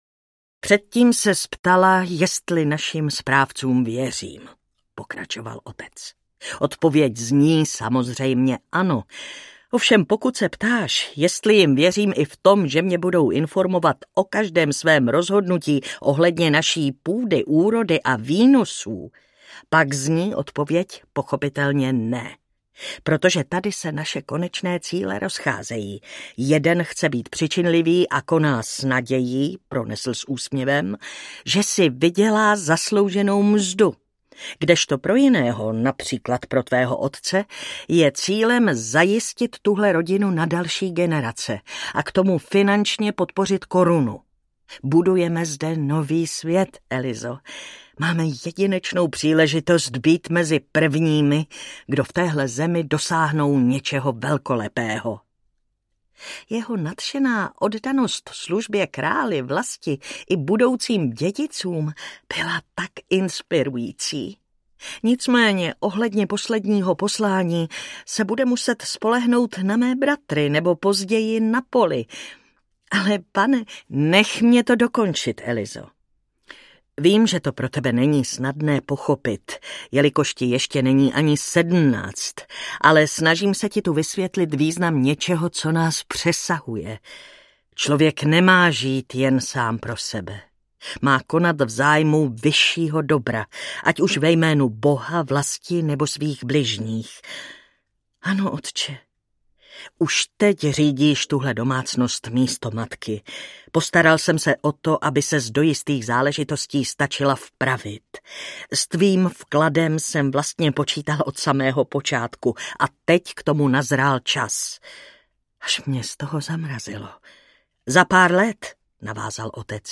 Dívka s vášní pro indigo audiokniha
Ukázka z knihy
Čte Martina Hudečková.